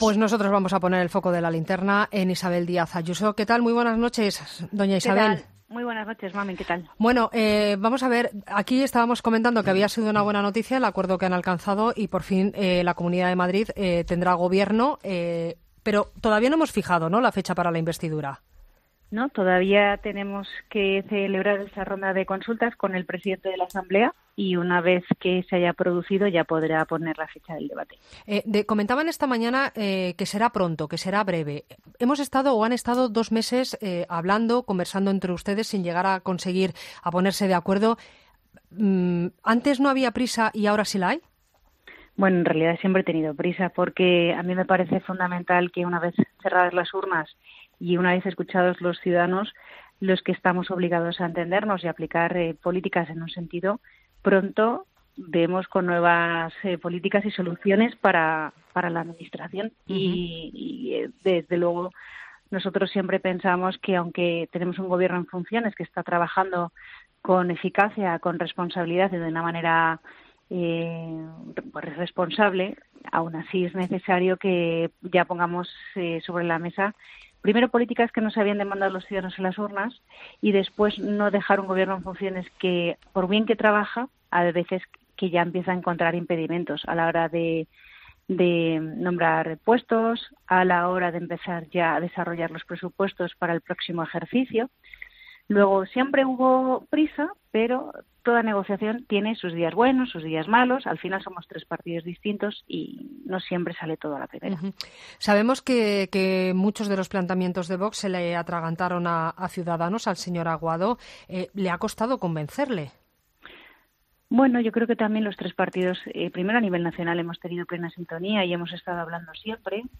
La candidata del Partido Popular a la Comunidad de Madrid ha estado en ‘La Linterna’ de COPE el mismo día que ha confirmado que podrá formar gobierno con el apoyo de Ciudadanos y Vox después de varias semanas de negociaciones y una negativa en la primera sesión de investidura.